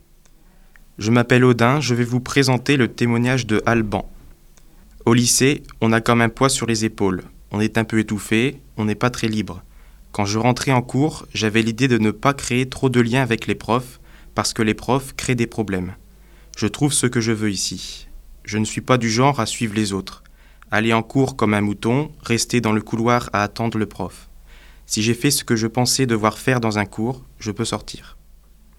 étudiants français et chinois de l'Université Lille 1 qui ont prêté leur voix.